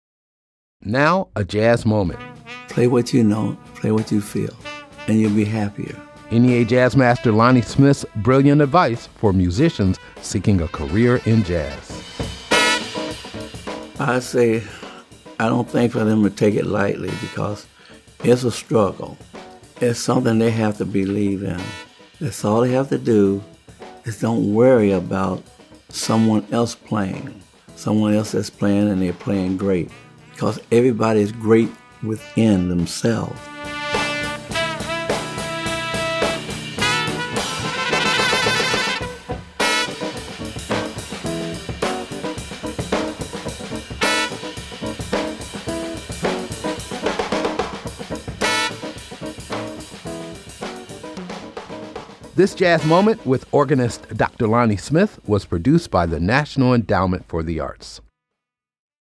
Excerpt of “Think” composed by Aretha Franklin and Ted White and performed by Dr. Lonnie Smith, from the album THINK!, used courtesy of Blue Note Records (Universal Music) and by permission of Fourteenth Hour Music Inc. [BMI]